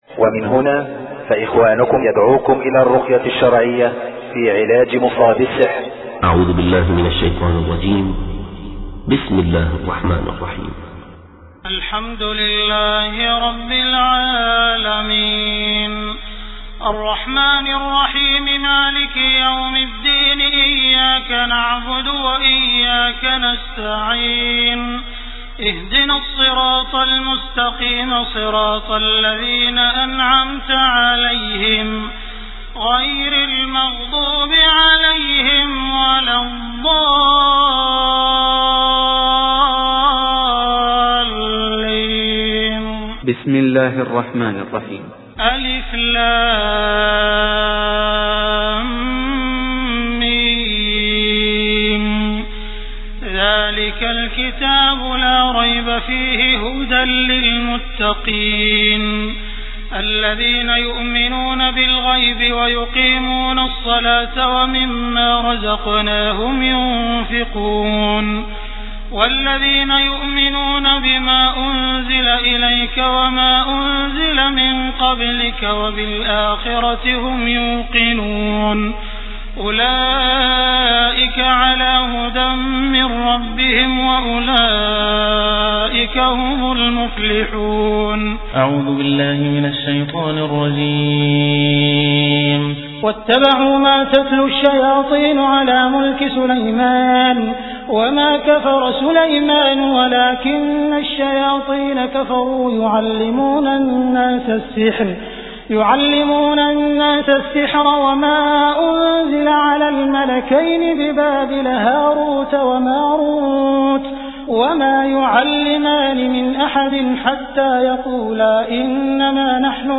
Title: الرقية الشرعية&السديس - Views: - 2869 - - Uploaded: 18-09-11